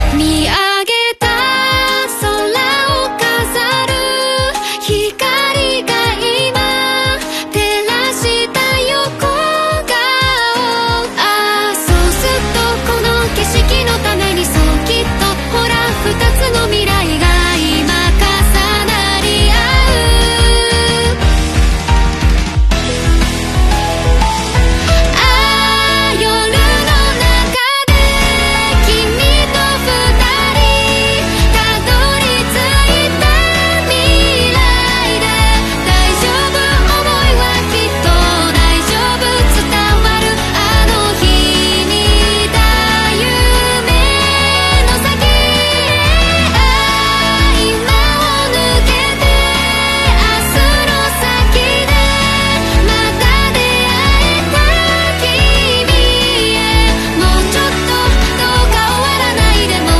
Anime Voice fansdub, Dr. Stone sound effects free download